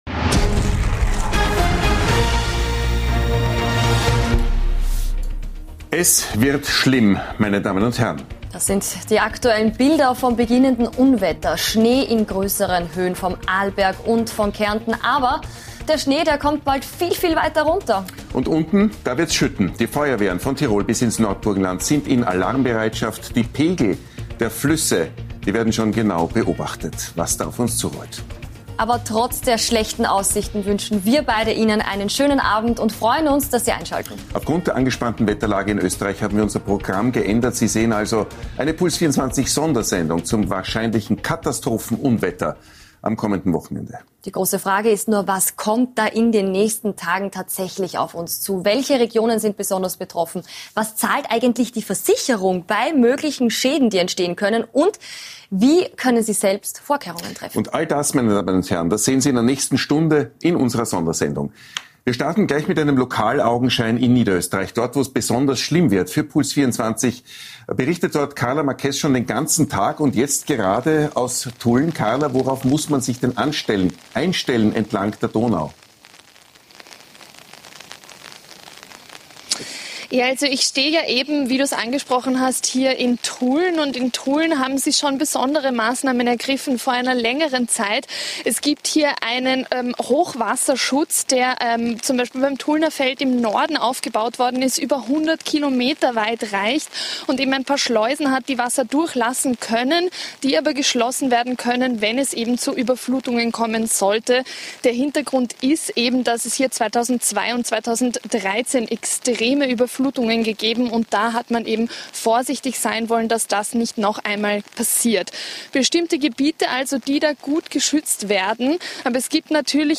live im Studio
direkt aus den gefährdeten Gebieten